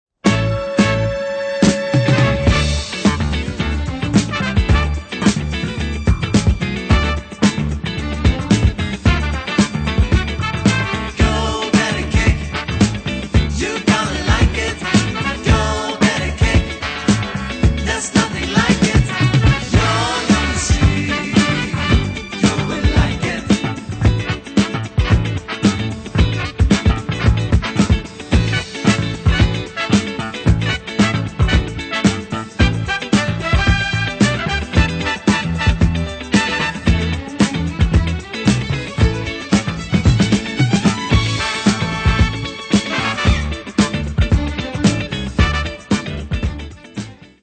funny medium voc.